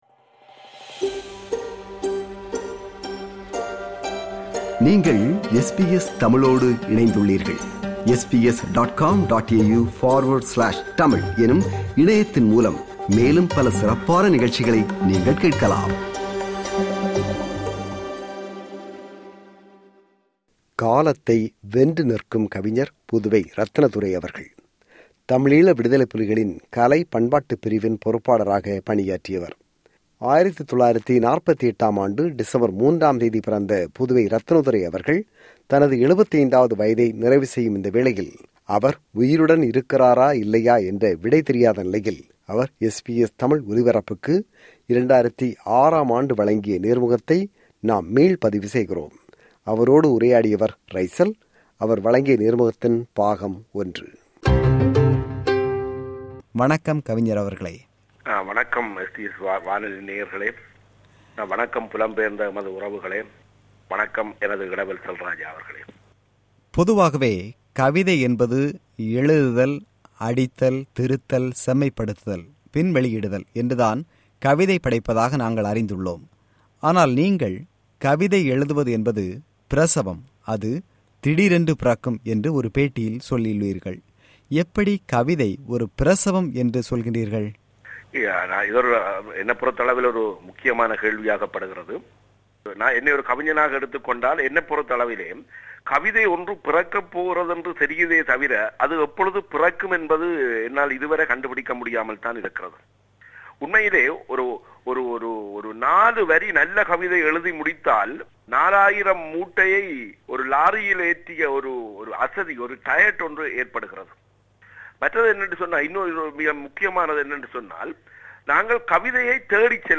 நேர்முகம் – பாகம் 1.